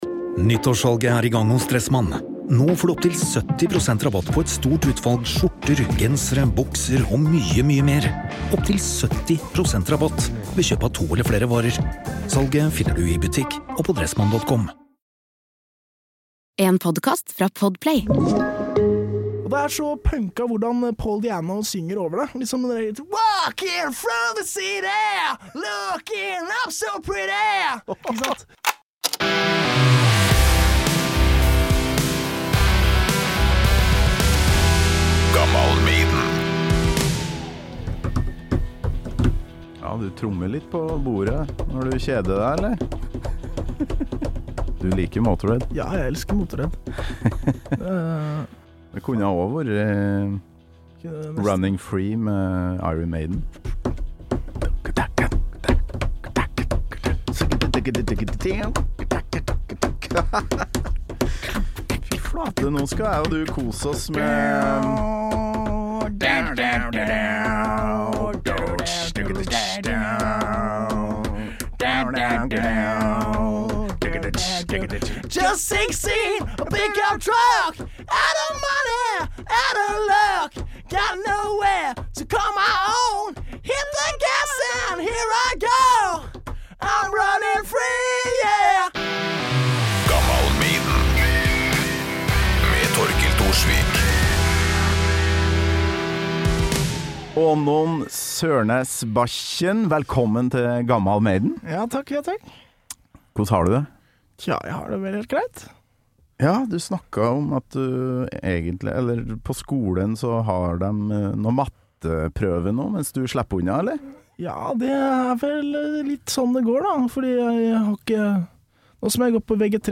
Gjør deg klar for mye tromming på bord, synging og nørding rundt Maiden, AC/DC, Powerage, Stooges, Iggy, KISS og så videre og så videre.